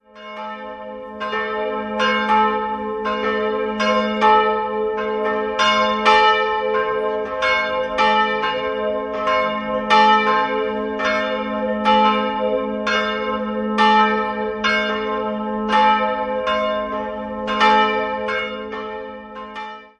Jahrhundert als Gnadenbild verehrt wurde. 2-stimmiges Kleine-Terz-Geläute: a'(-)-c'' Beide Glocken wurden von Johann Hahn gegossen, die größere 1927, die kleinere bereits 1901.